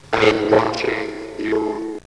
1997, installation.
Behind a manipulated image of the artist lies a looped recording of a computerized voice whispering: "